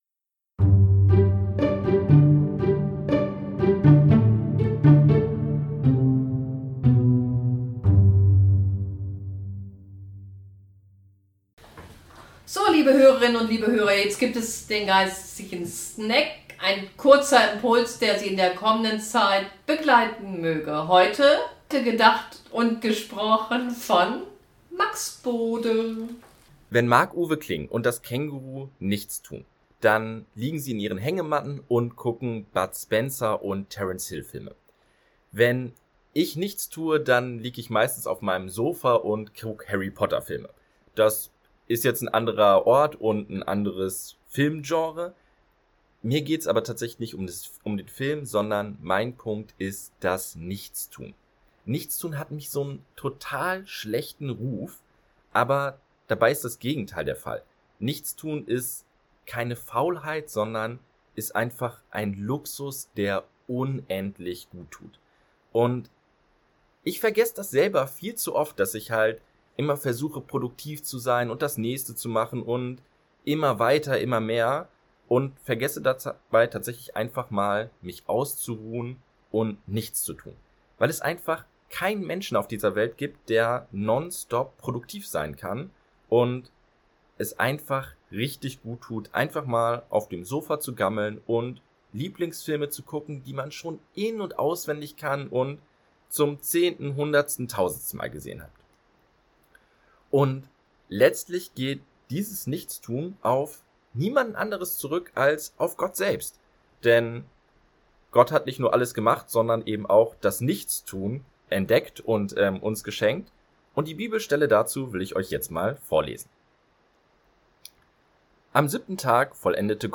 Geistlicher Snack: Nichtstun